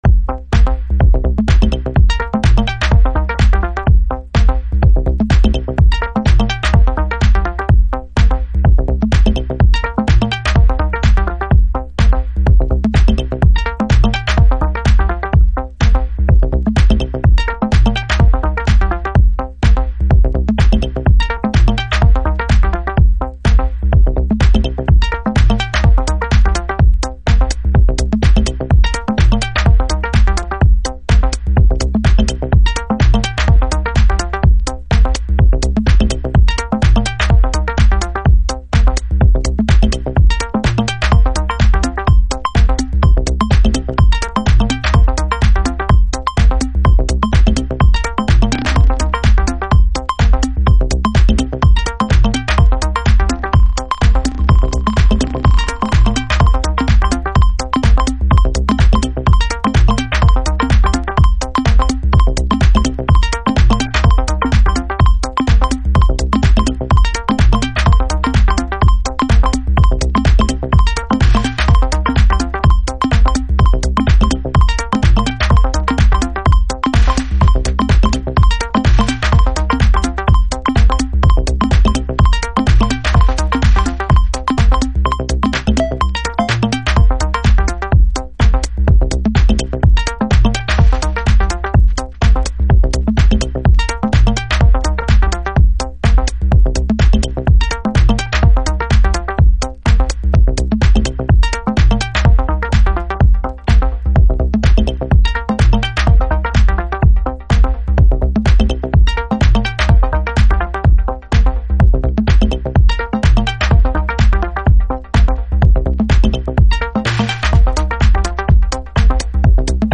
House / Techno
シカゴハウスのミニマルな要素を抽出したメロディックテクノ。